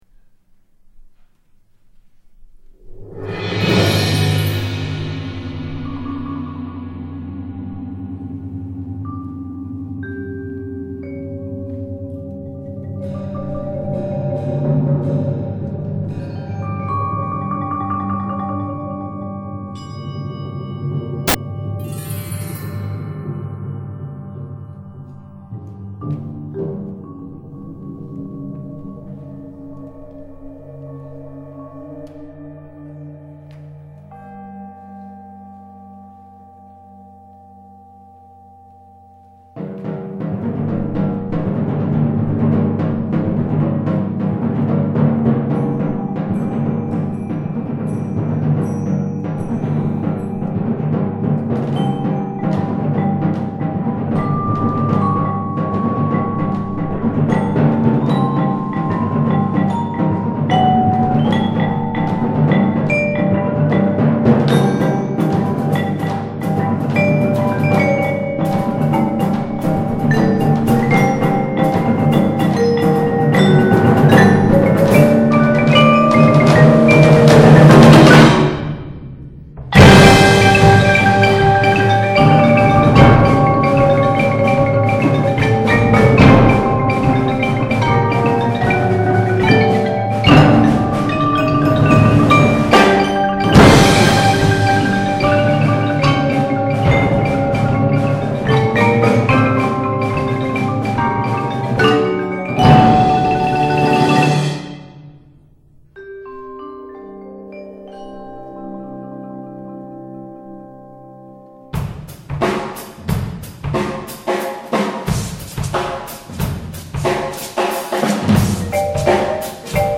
Genre: Percussion Ensemble
# of Players: 11
Player 1 – Bells, Chimes, Low Tom, Maracas
Player 2 – Xylophone, Chimes, Low Tom
Player 3 – Vibraphone, Temple Blocks, China Cymbal
Player 4 – Vibraphone, Claves, Brake Drum
Player 5 – Marimba, Suspended Cymbal
Player 8 – Timpani
Player 11 – Drum Set
Drums, featured later, add something of a tribal flavor.
It represents a feeling of quiet loneliness or solitude.